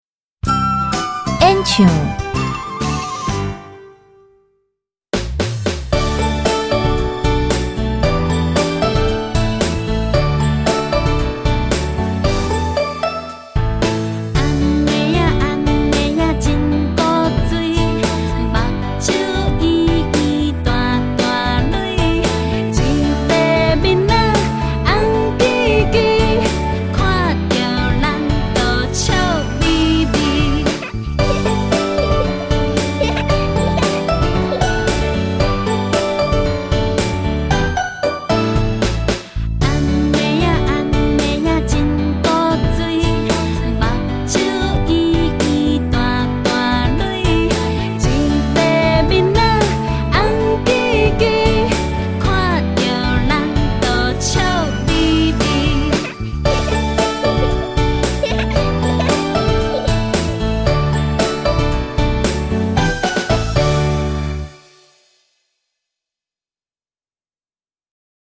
.第一課演唱